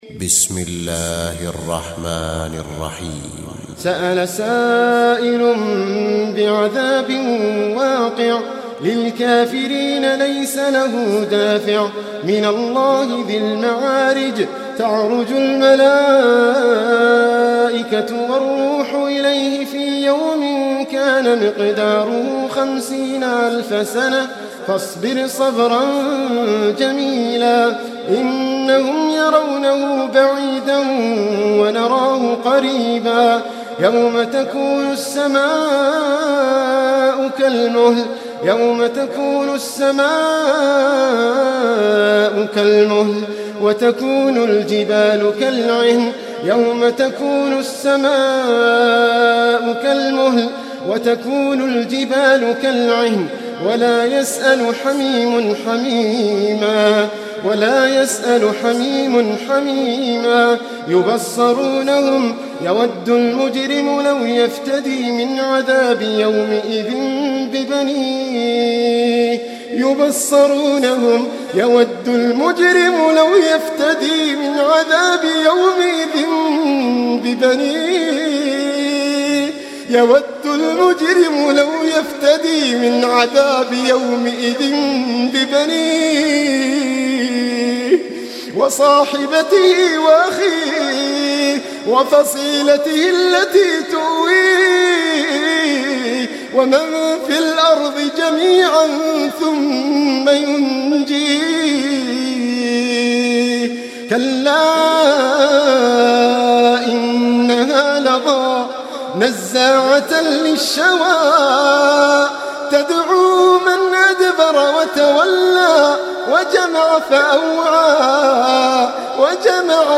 Surah Sequence تتابع السورة Download Surah حمّل السورة Reciting Murattalah Audio for 70. Surah Al-Ma'�rij سورة المعارج N.B *Surah Includes Al-Basmalah Reciters Sequents تتابع التلاوات Reciters Repeats تكرار التلاوات